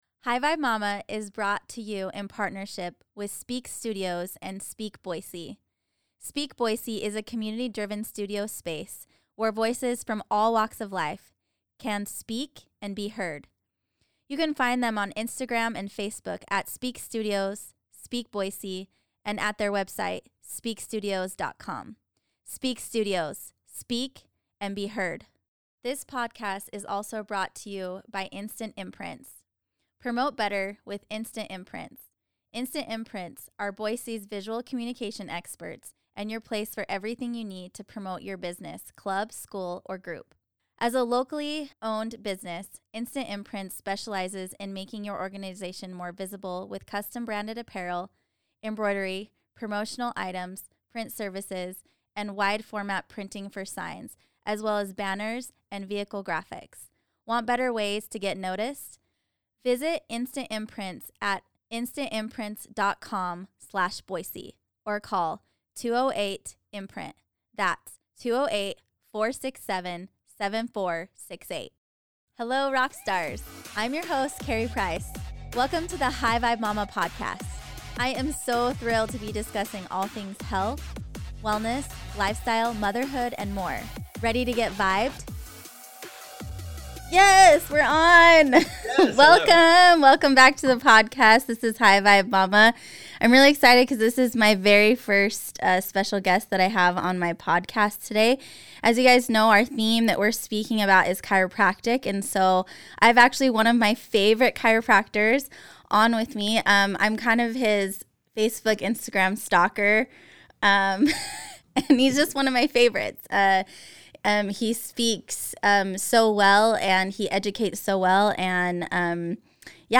This interview was FIRE.